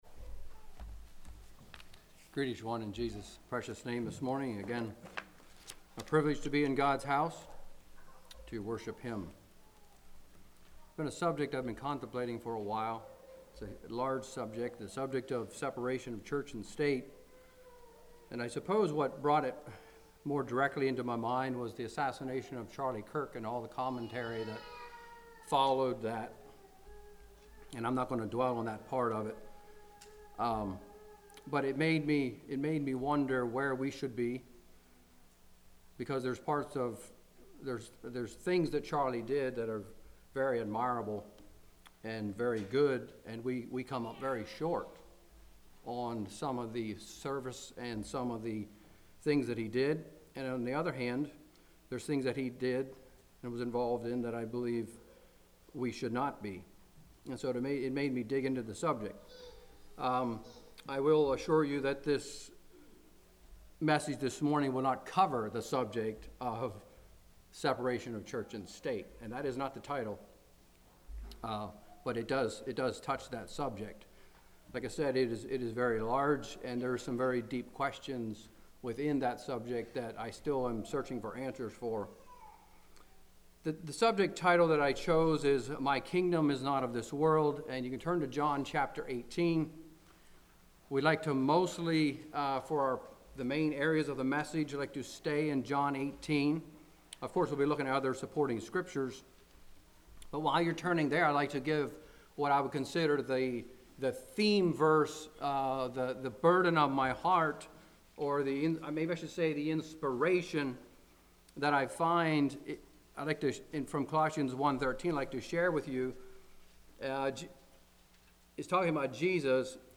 Congregation: Blue Ridge